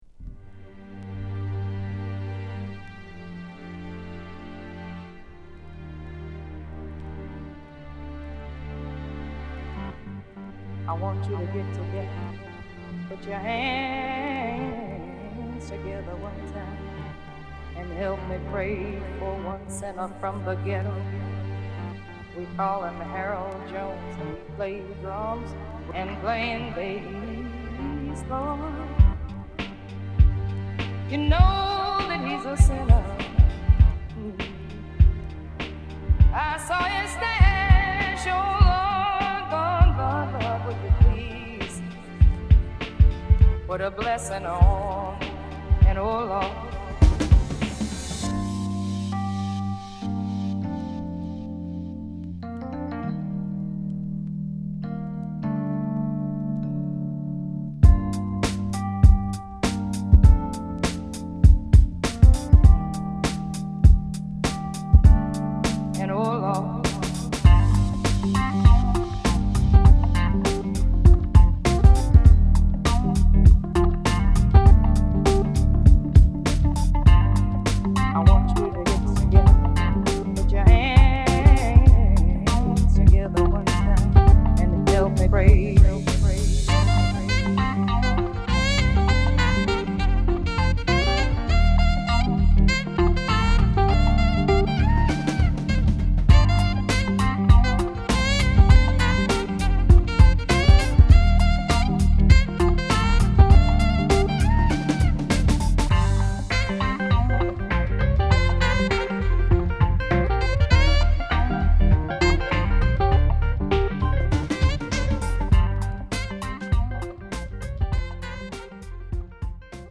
90年代UKソウルからの〜ダウンテンポを継いだ様なトラックにヴォーカルをはめたリミックスを披露！